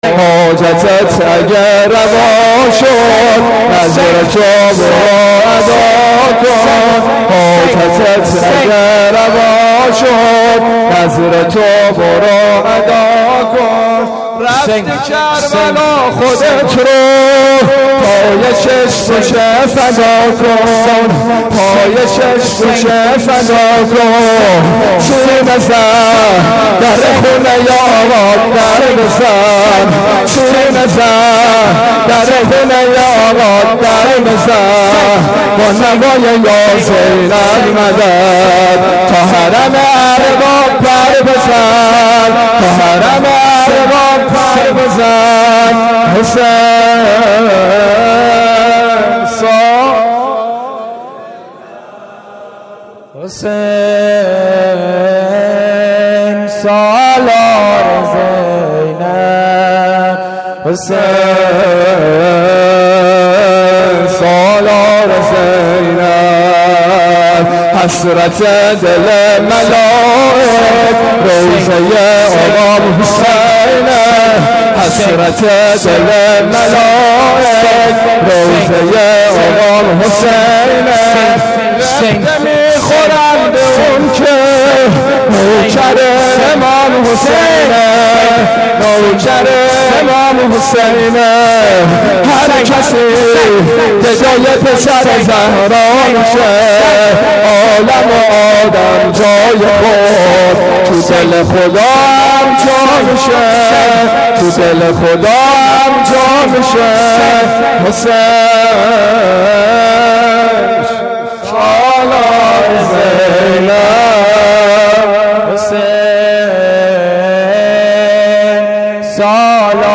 شب-عاشورا-شور.mp3